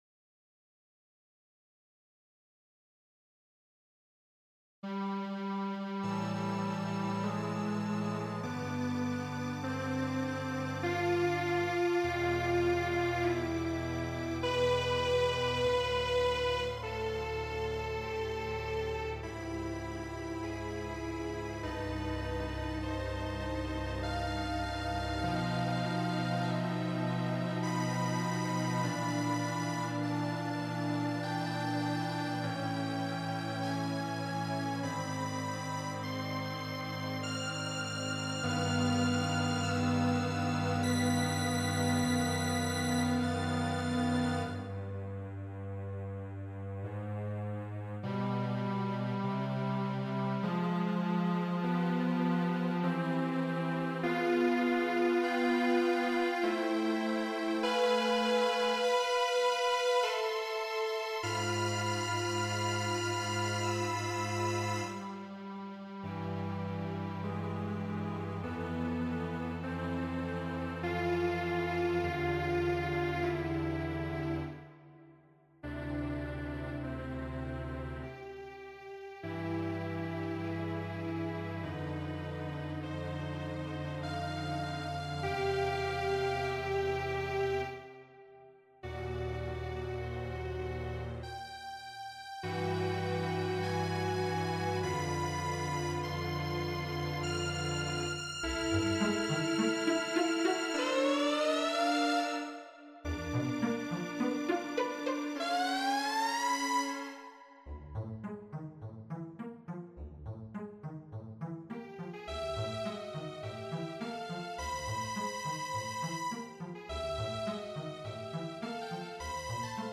String quartet written for the Knebworth Flower Festival 2007